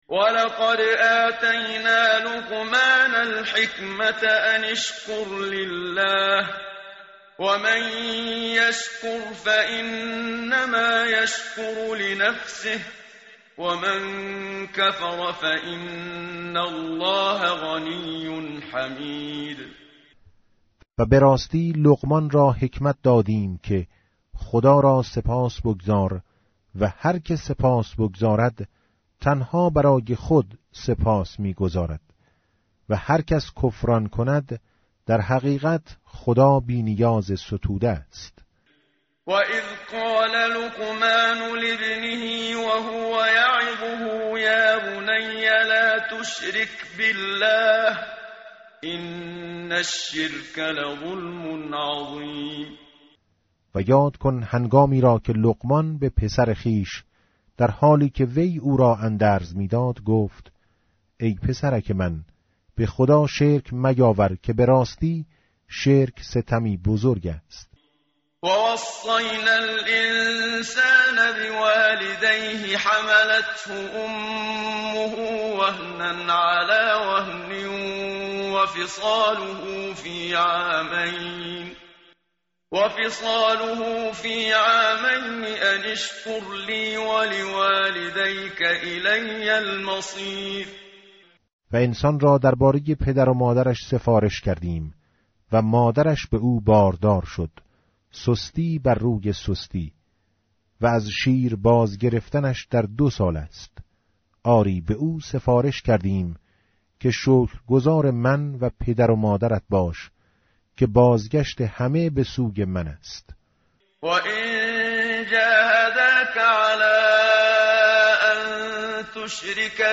متن قرآن همراه باتلاوت قرآن و ترجمه
tartil_menshavi va tarjome_Page_412.mp3